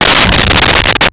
Sfx Whoosh 5001
sfx_whoosh_5001.wav